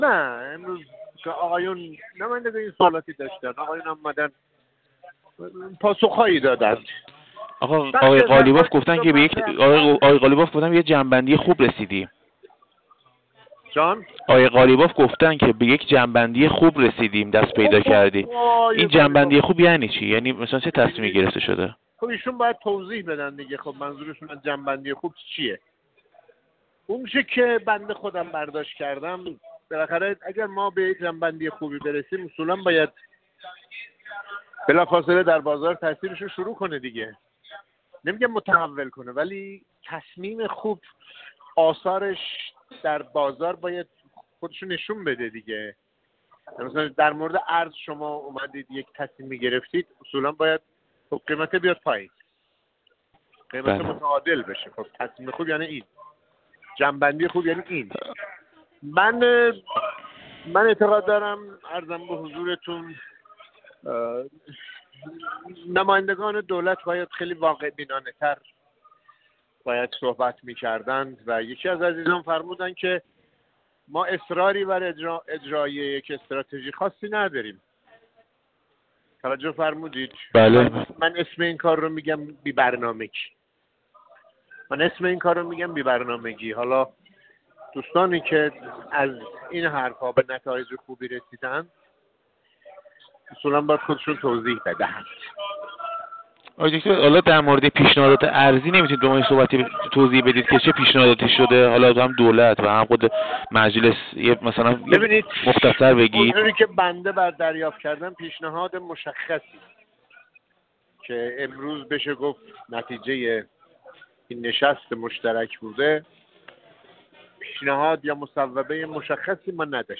عباس‌زاده مشکینی در گفت‌وگو با ایکنا خبر داد: